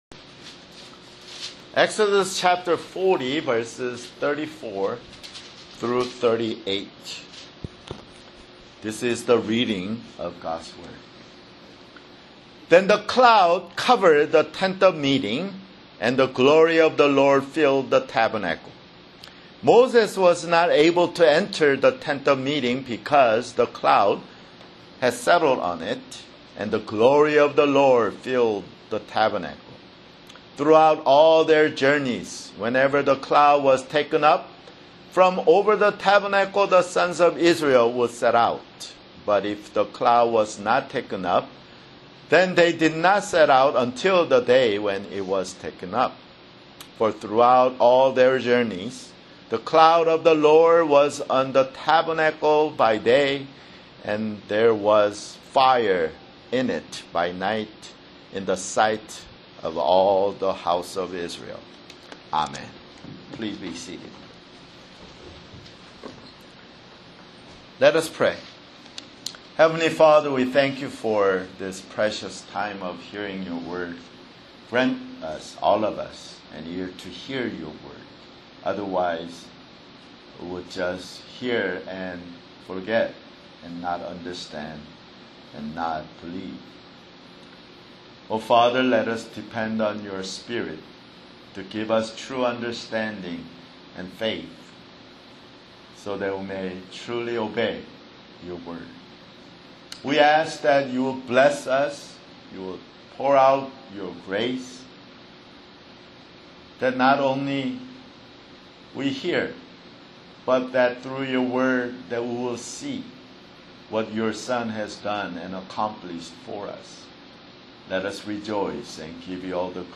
[Sermon] Exodus (100)